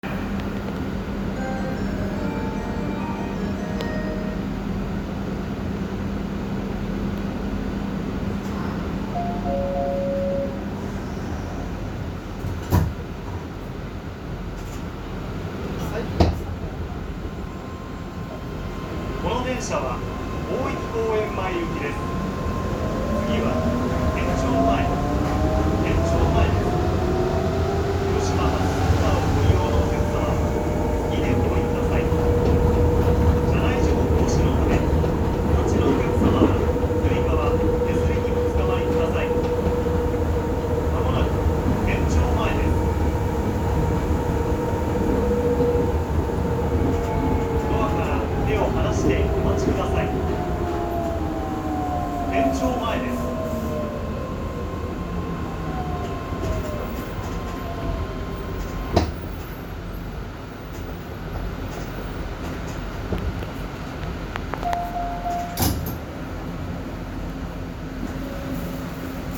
・7000系走行音
本通→県庁前 / 県庁前→城北 / 新白島→白島 / 大町→毘沙門台
ごく普通の東洋IGBTとなります。6000系が異端を極めた走行音だったのとは対照的ですね。